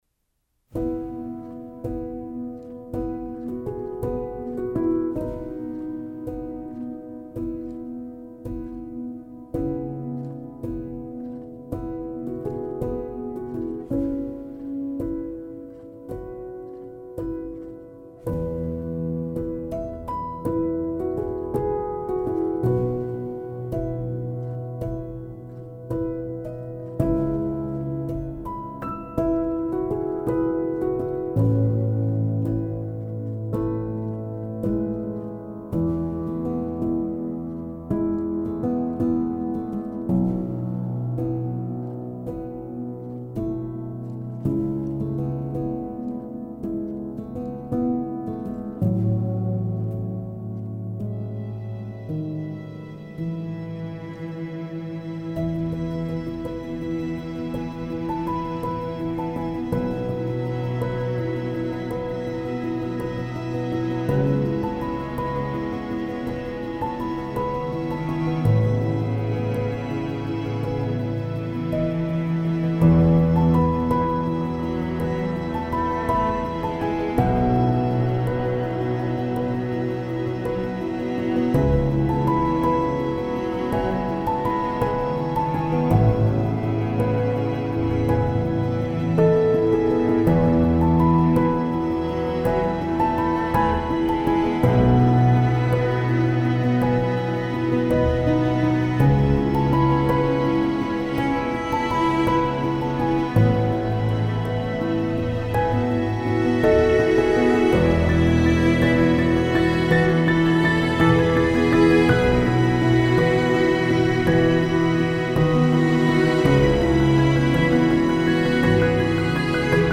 موسیقی بی کلام الهام‌بخش امید‌بخش پیانو مدرن کلاسیک